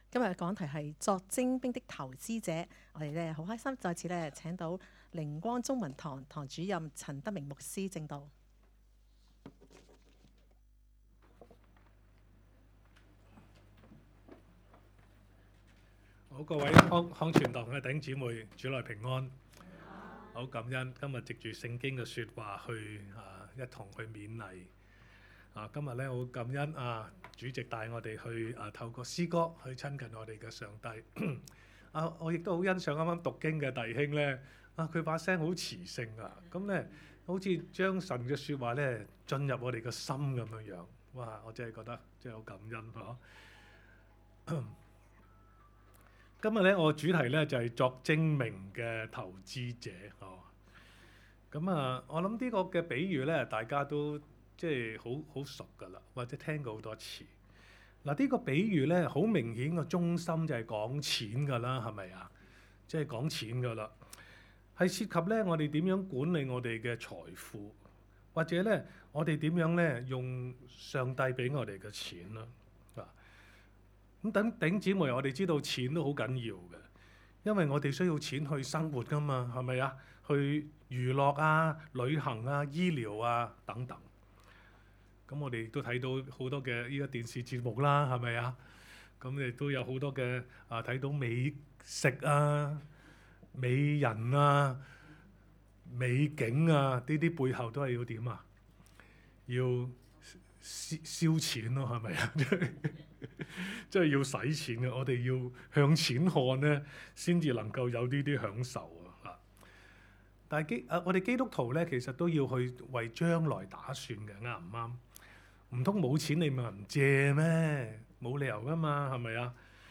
講道 : 作精問的投資者 讀經 : 路1 6:1–1 3